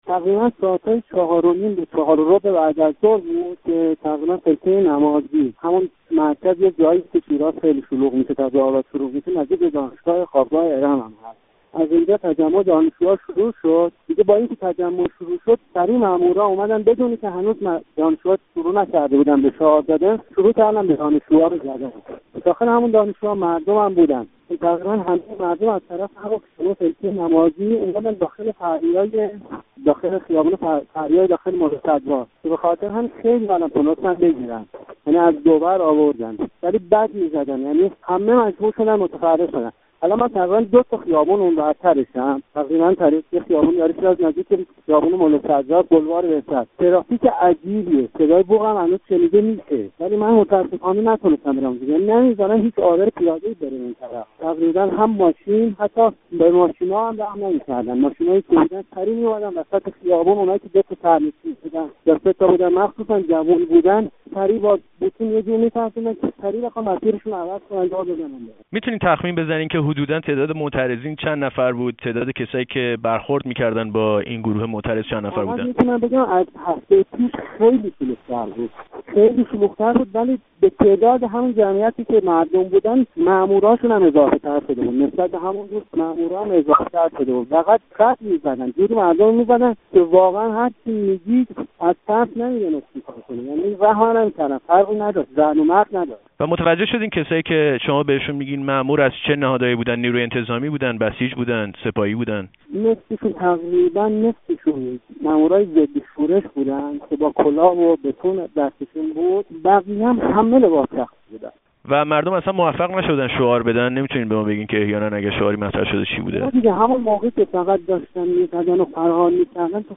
گفت و گوی
با یک شاهد عینی در شیراز